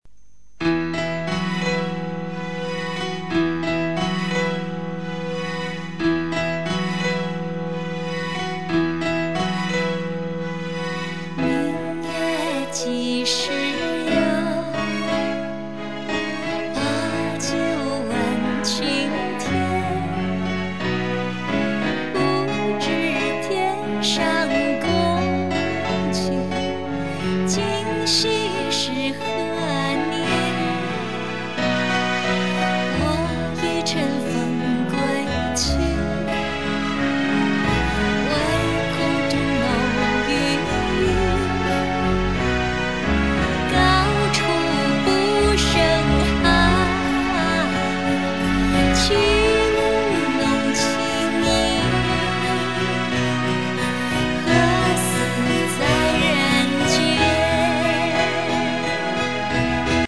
将左右声道分开的卡啦OK带处理成纯伴奏带操作事例
将mixer.mp3的左右声道分开,左声道是人声演唱,右声道为伴奏音乐,所以我们取right声道波型为原料,这样处理出来左右声道都是伴奏怎么播放都不会有问题